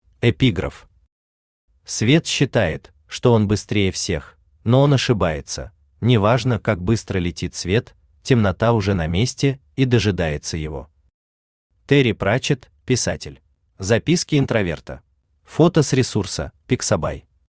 Аудиокнига Размышления на диване | Библиотека аудиокниг
Aудиокнига Размышления на диване Автор Валерий Муссаев Читает аудиокнигу Авточтец ЛитРес.